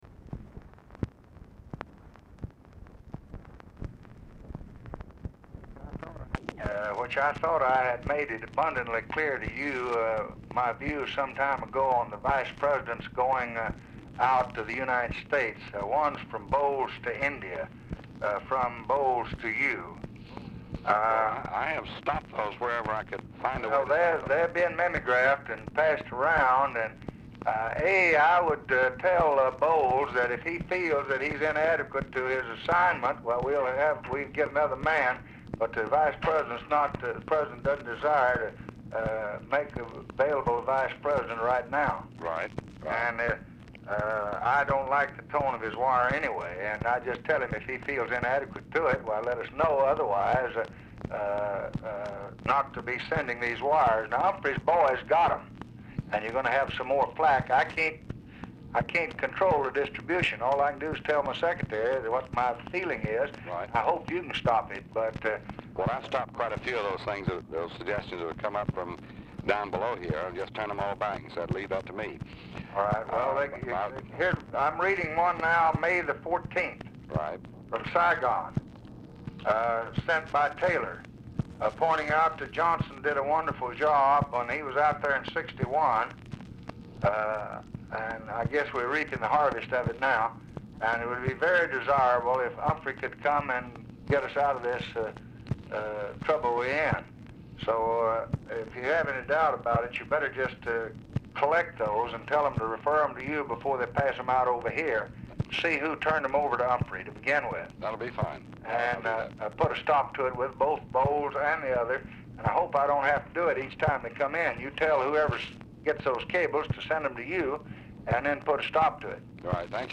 Telephone conversation # 7827, sound recording, LBJ and DEAN RUSK
RECORDING STARTS AFTER CONVERSATION HAS BEGUN
Format Dictation belt